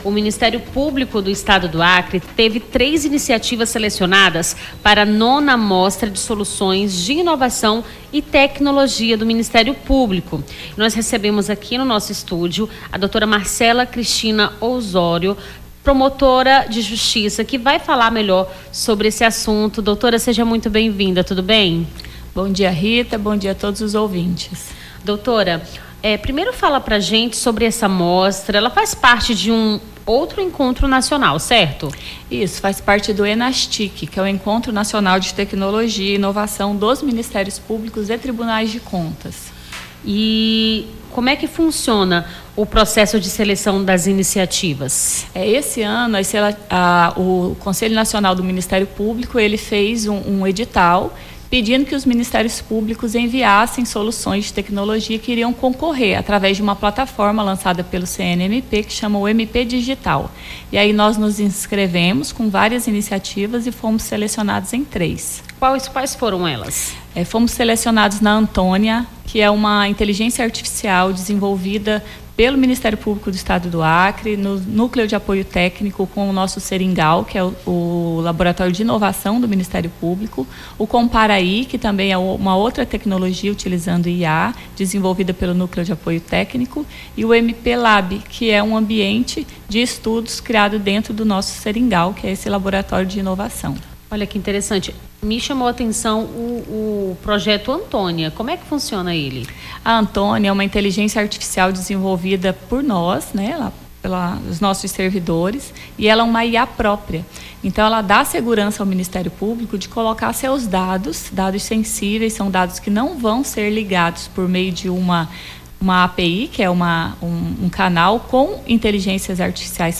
Nome do Artista - CENSURA - ENTREVISTA MPAC SOLUÇÕES (20-02-25).mp3